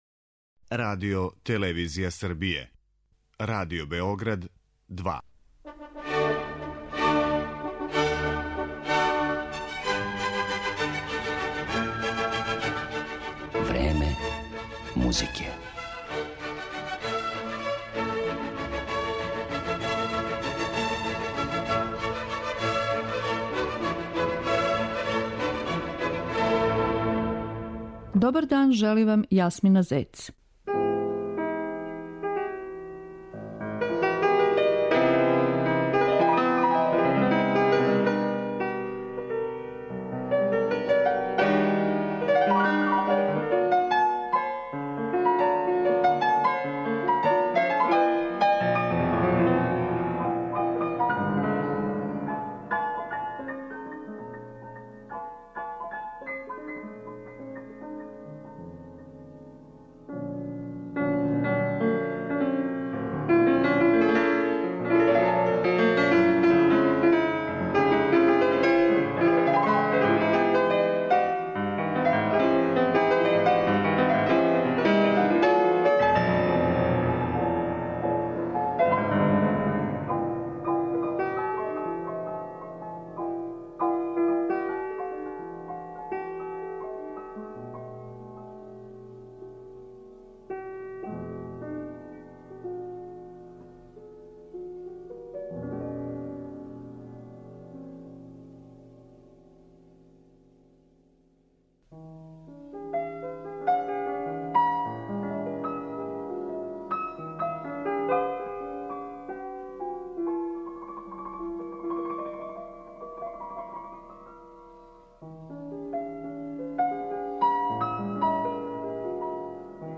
Емитоваћемо клавирска дела Александра Скрјабина у интерпретацији славног руског пијанисте Владимира Софроницког.
Слушаоци ће моћи да чују делове последњих концерта Софроницког, које је одржао у Москви 1960. године.